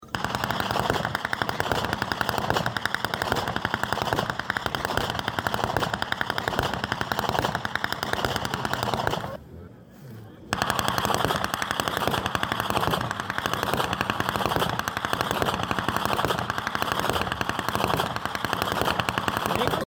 50. Ainringer Gemeindepreisschnalzen in Perach am 02.02.2025